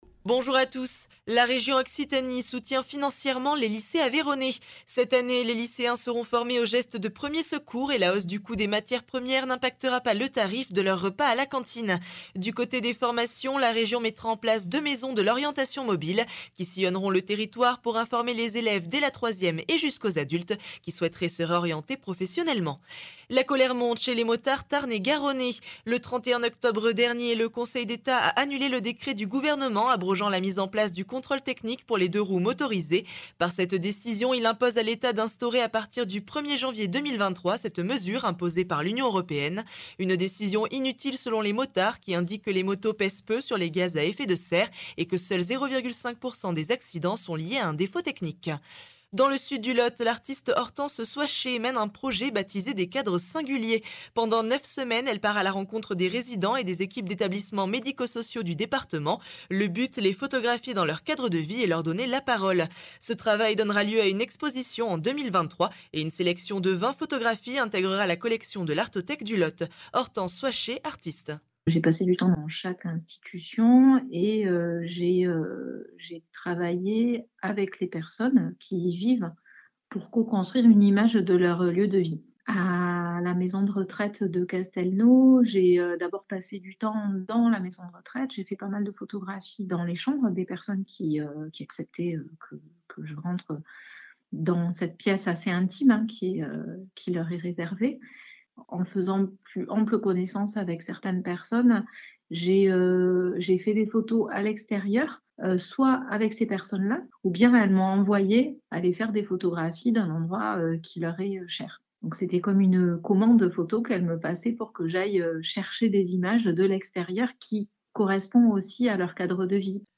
L’essentiel de l’actualité de la région Occitanie en 3 minutes. Une actualité centrée plus particulièrement sur les départements de l’Aveyron, du Lot, du Tarn et du Tarn & Garonne illustrée par les interviews de nos différents services radiophoniques sur le territoire.